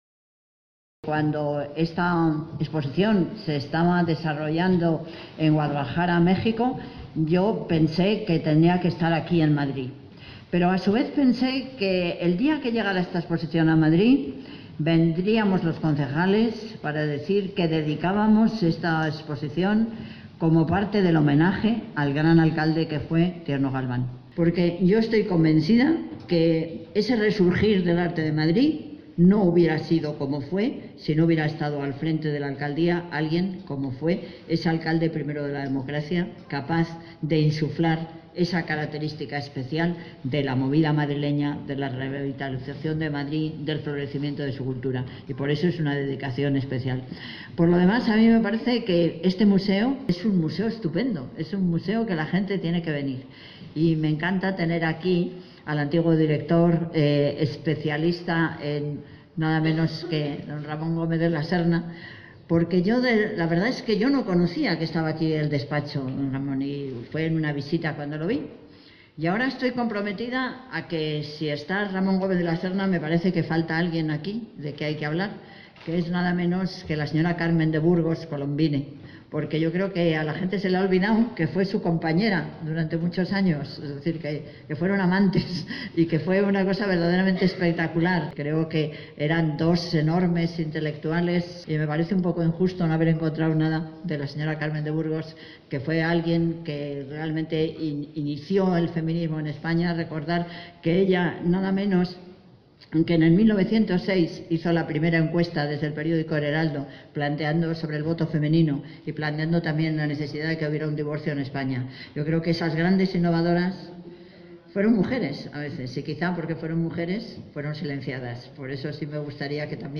MCarmenaExposicionPongamosQueHabloDeMadrid-12-12.mp3